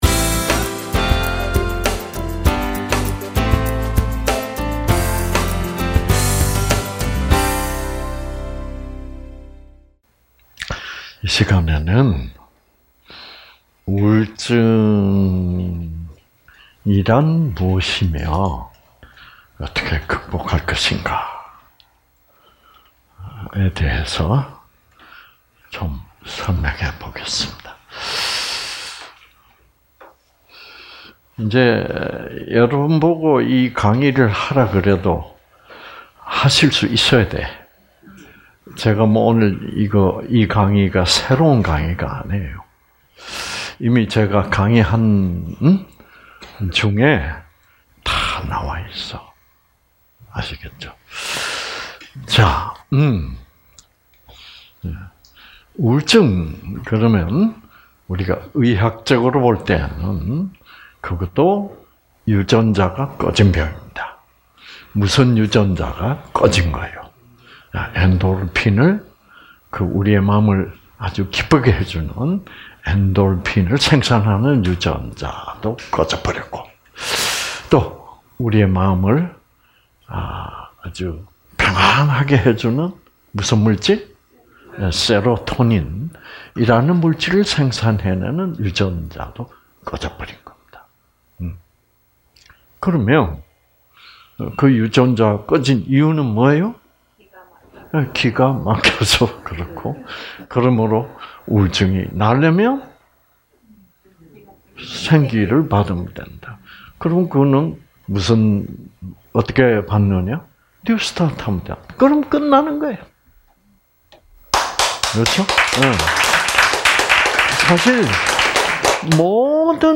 세미나 동영상 - 제244기 1부 프로그램 (2020.9.5~9.14)